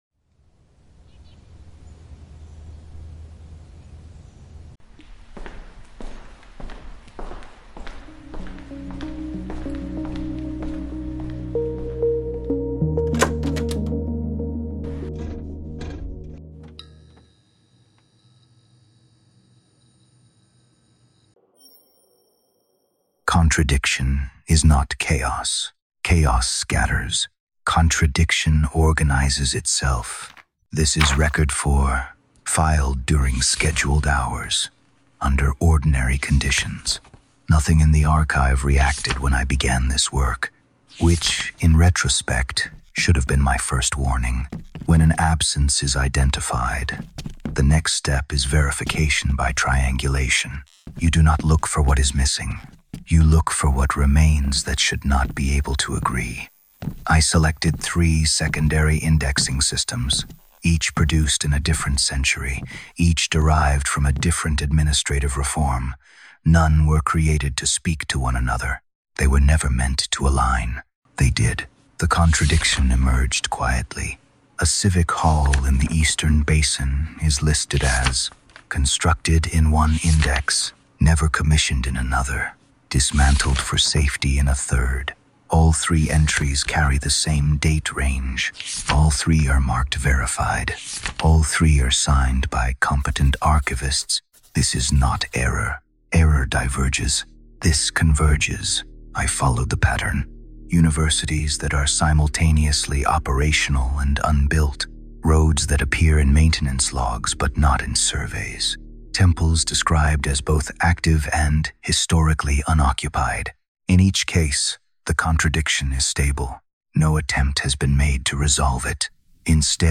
Record 04: The Index That Contradicts Itself – Narration